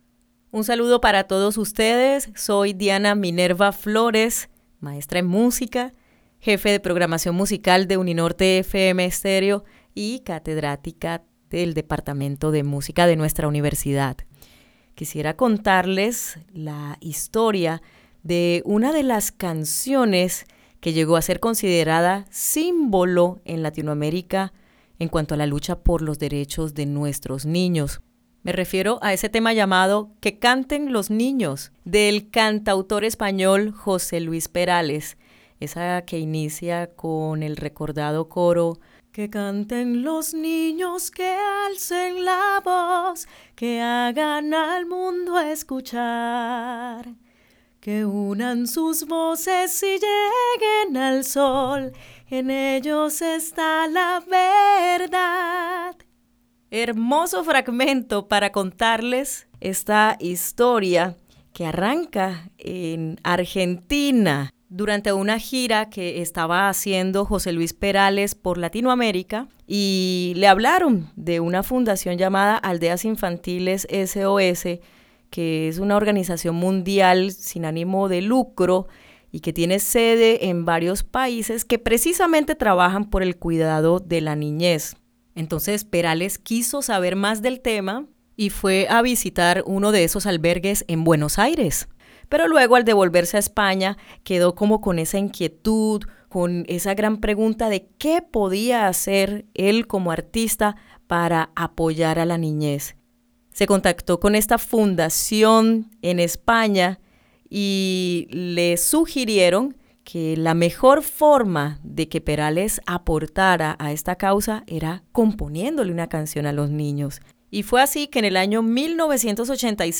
maestra en Música